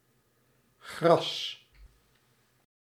Ääntäminen
France: IPA: [ɛʁb]